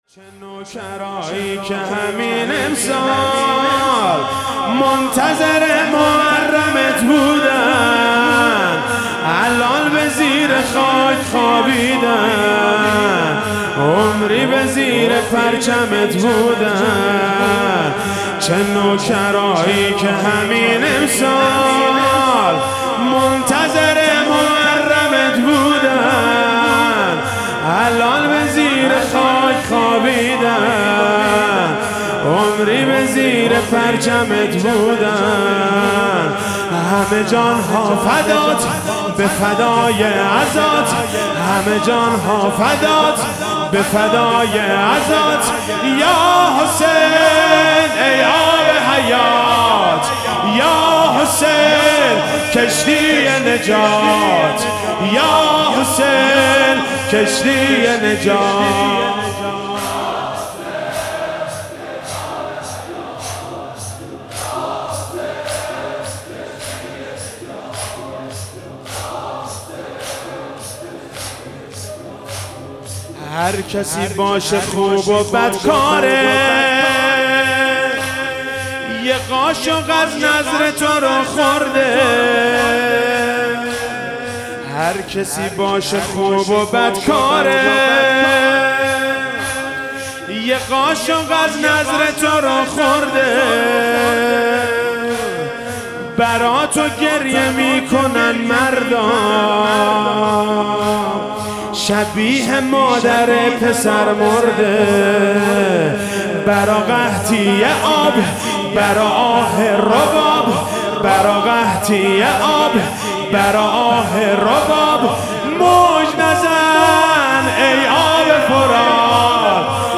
شب عاشورا محرم 97 - زمینه - چه نوکرایی که همین امسال منتظر